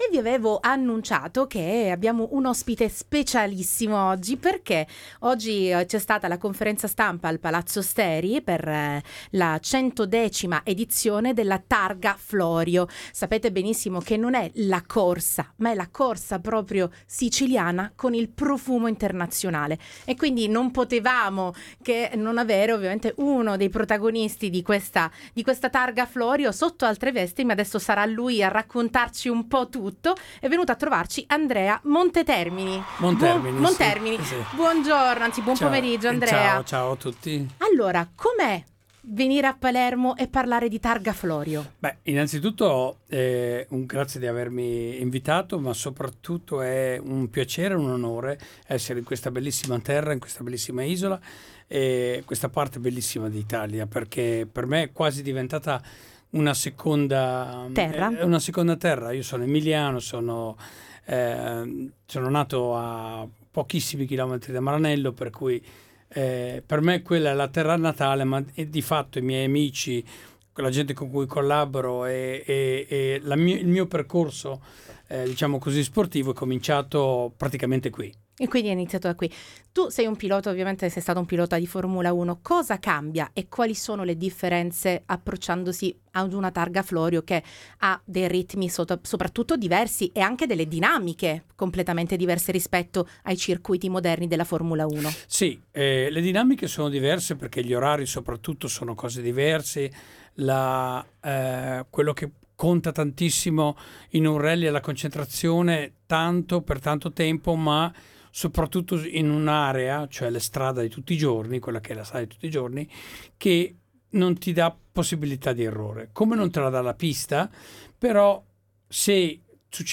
INTERVISTA ANDREA MONTERMINI